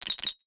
NOTIFICATION_Metal_13_mono.wav